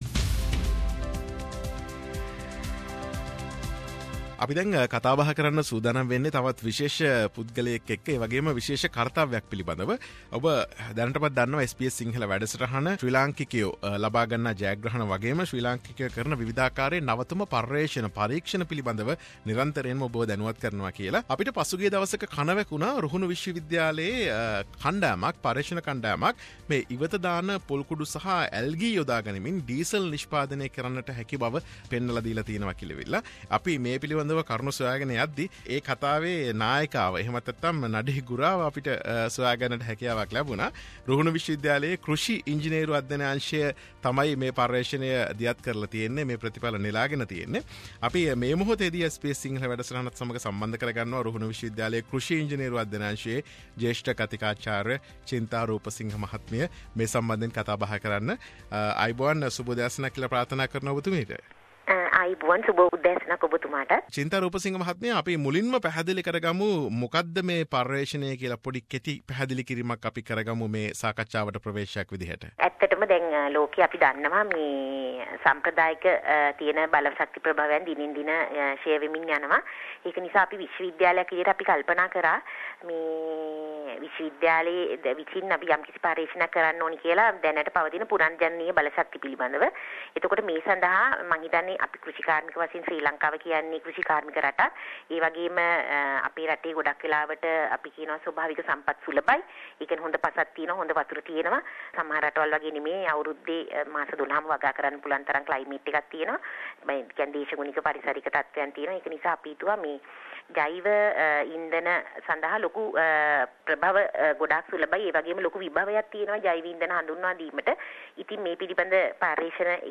As a solution to the fuel crisis, a group of researchers from the Ruhunu University have come up with a method of producing diesel out of used coconut scrapings and algae. This is a discussion